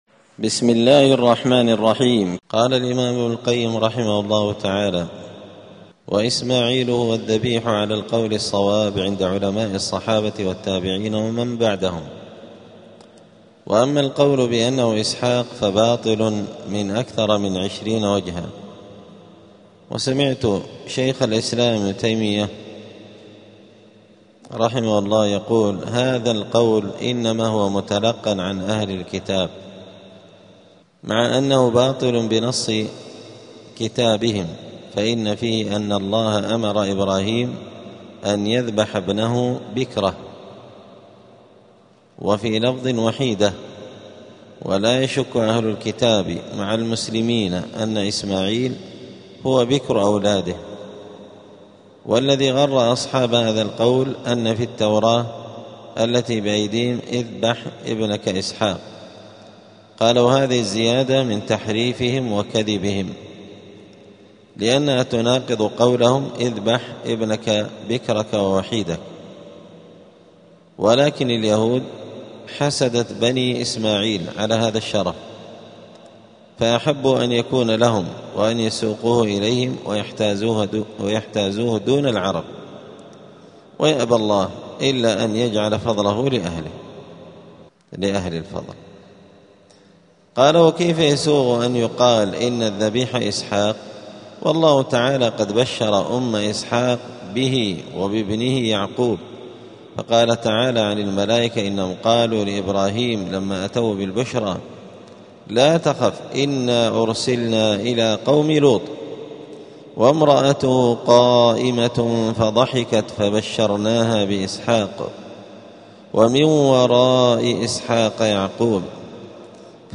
*الدرس الحادي عشر (11) {ﻓﺼﻞ في نسبه صلى الله عليه وسلم}.*